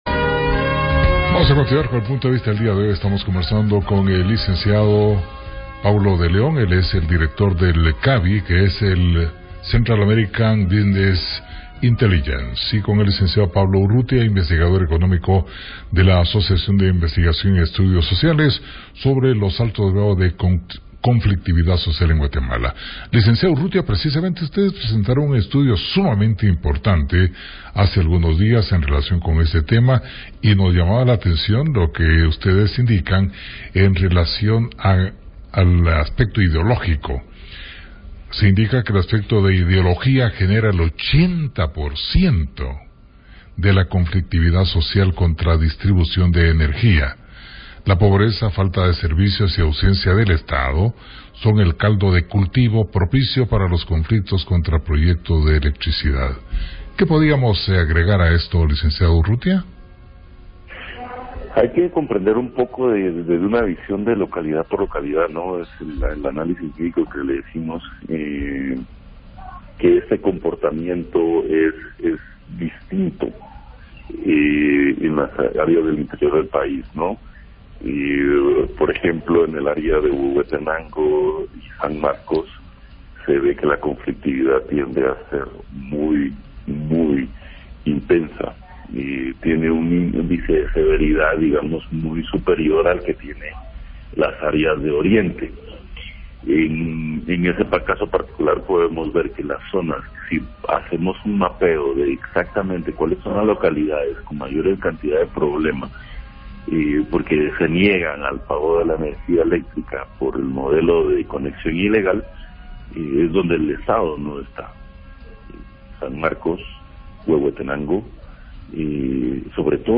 PUNTO DE VISTA / RADIO PUNTO: Entrevista